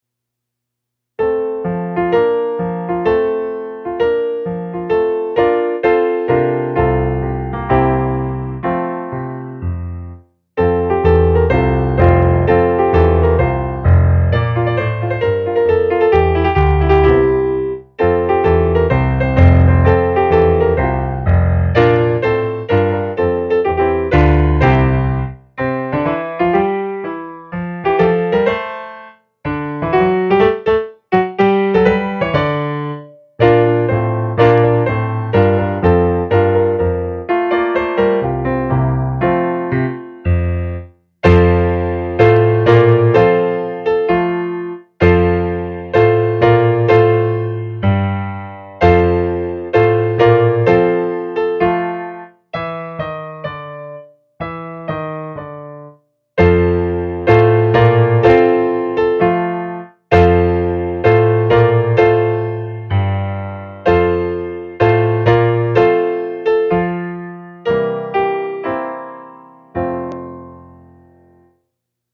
Time Signature: C
Key: F
Notations: Tempo marziale